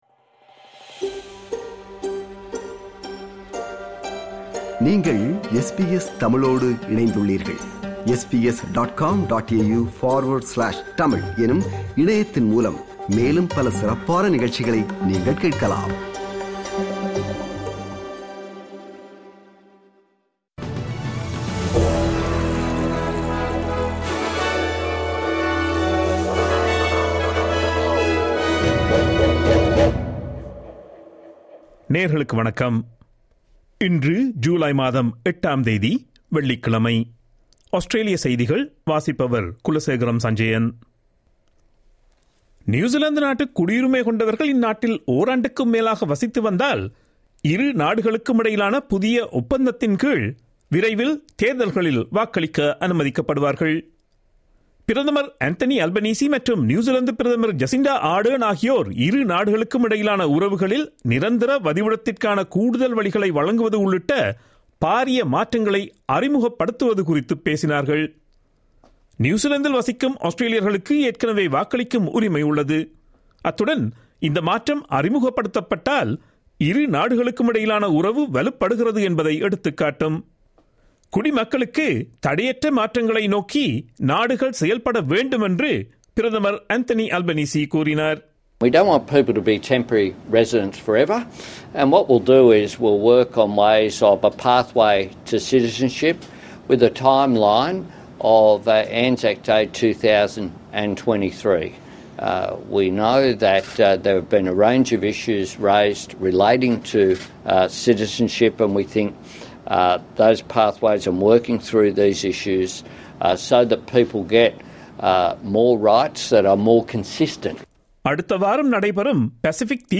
Australian news bulletin for Friday 08 July 2022.